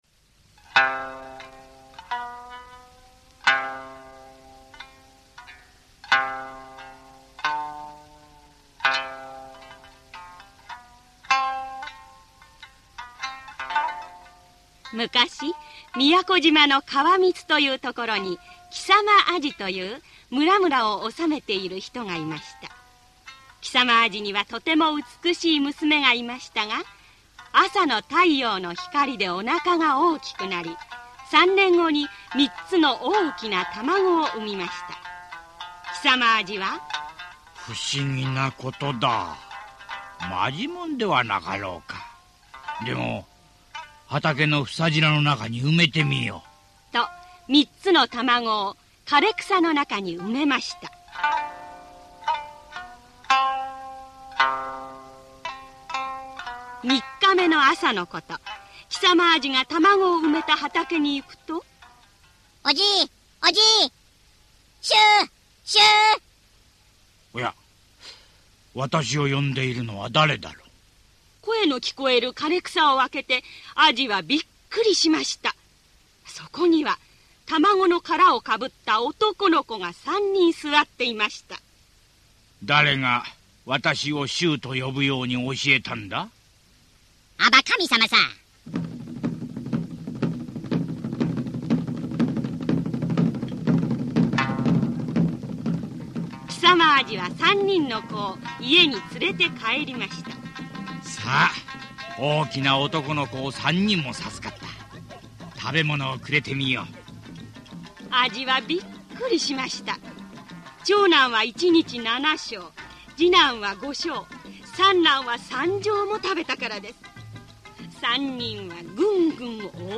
[オーディオブック] 来間島の村のはじまり